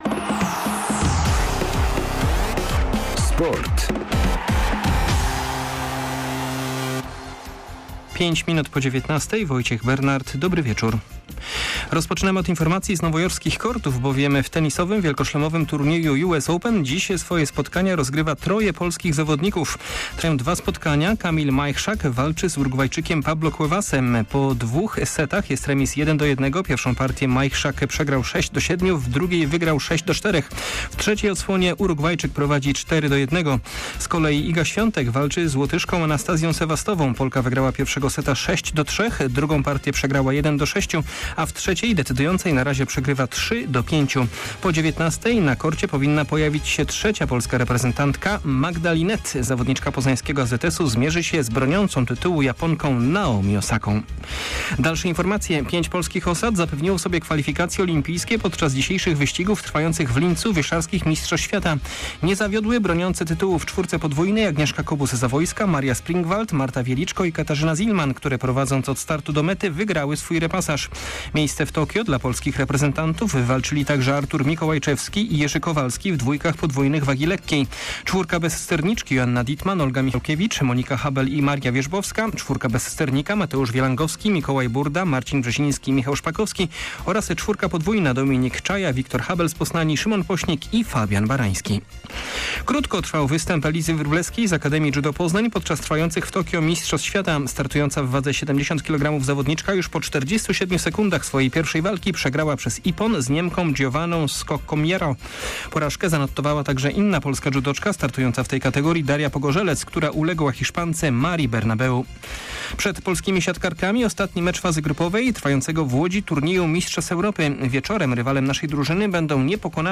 29.08. SERWIS SPORTOWY GODZ. 19:05